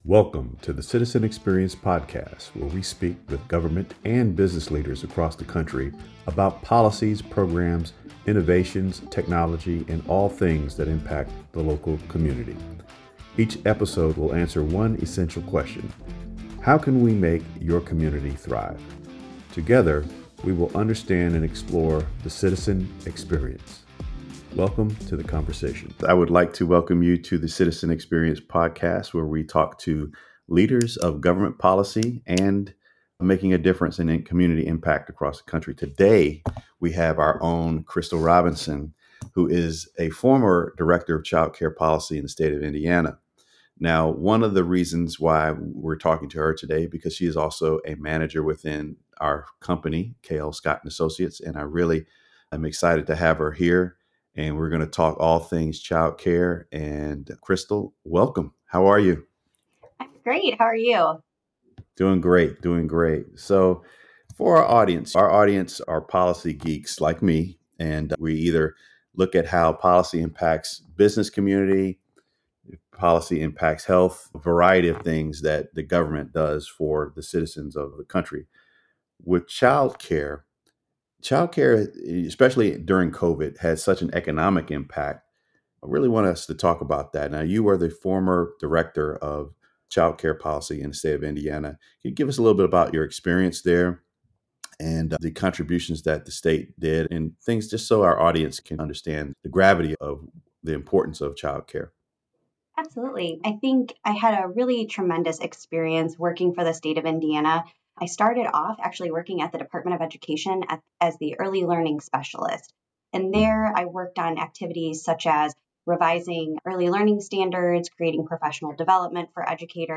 Together, they explore how outdated systems, unaffordable care, and lack of availability are pushing potential workers — especially women and single parents — out of the labor market. This conversation highlights why new voices are entering the room, demanding long-overdue policy changes and community-centered solutions.